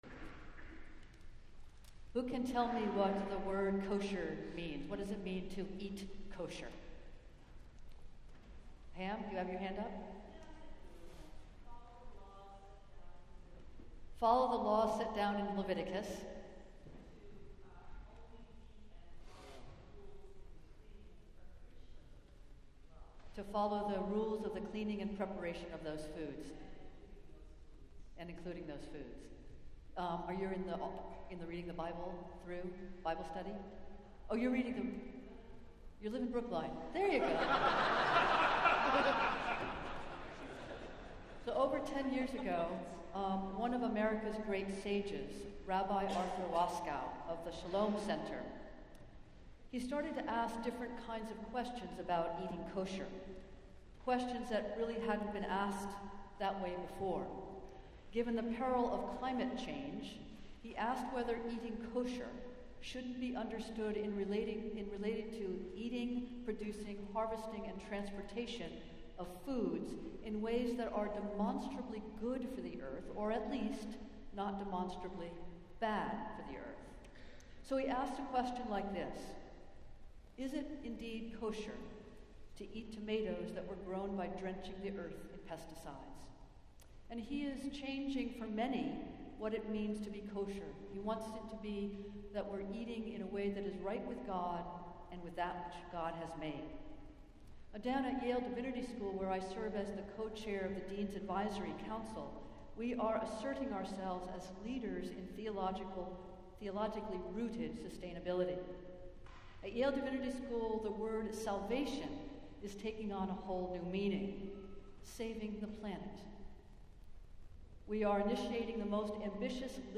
Festival Worship - Twenty-first Sunday after Pentecost
Preached on the occasion of the Blessing of the Mugs and the church’s revocation of the use of paper cups.